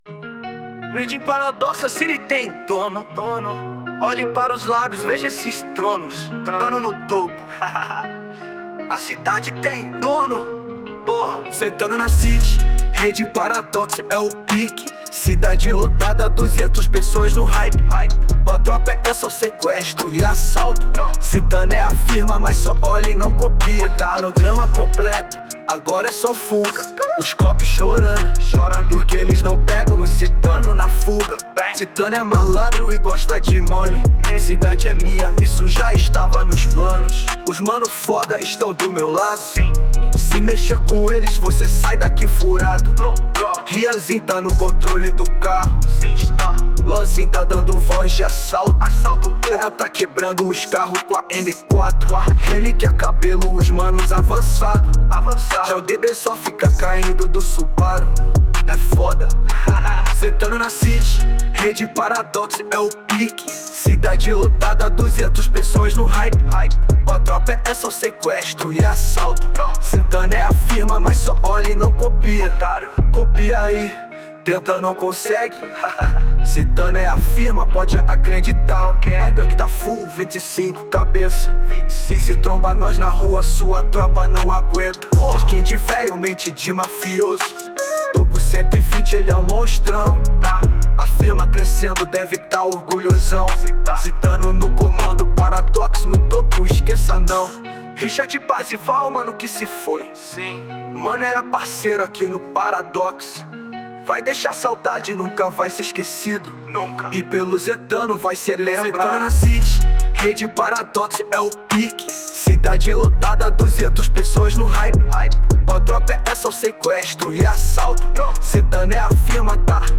2025-02-11 13:52:26 Gênero: Trap Views